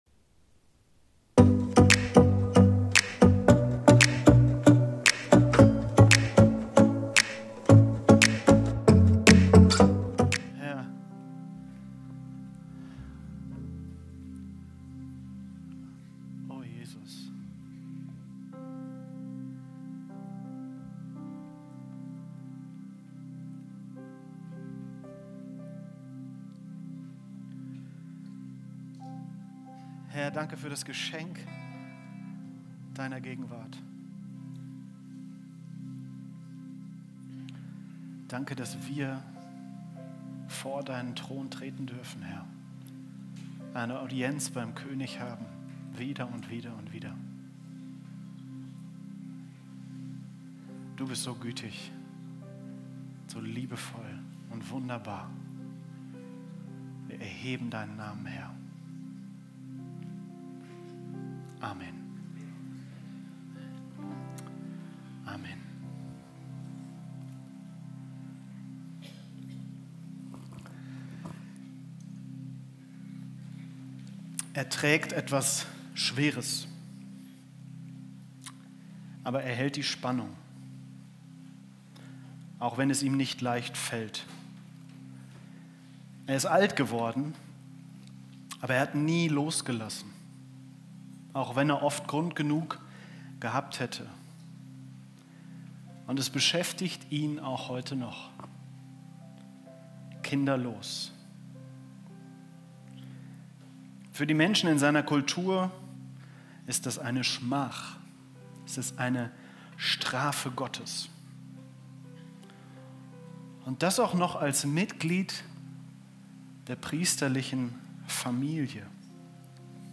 Live-Gottesdienst aus der Life Kirche Langenfeld.
Kategorie: Sonntaggottesdienst Predigtserie: Advent - eine Ankunft, die verwandelt